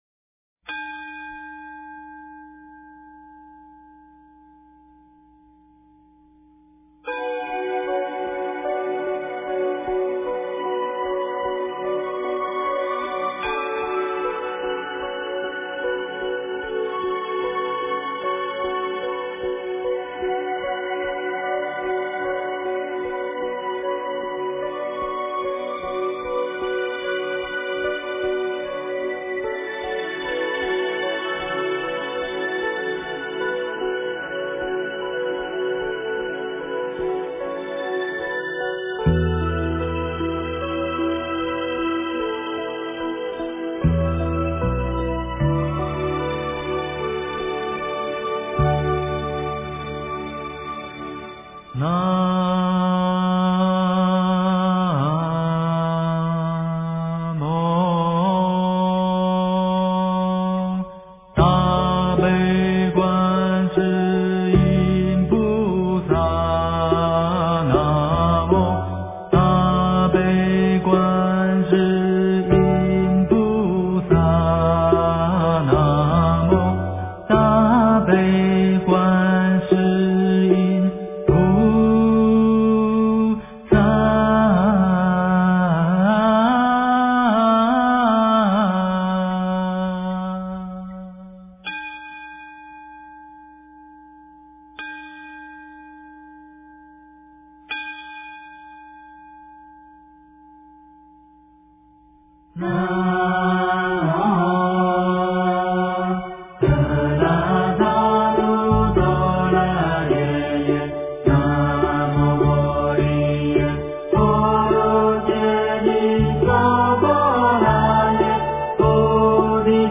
大悲神咒 诵经 大悲神咒--佚名 点我： 标签: 佛音 诵经 佛教音乐 返回列表 上一篇： 大悲咒 下一篇： 心经 相关文章 六字真言颂--怙主三宝 六字真言颂--怙主三宝...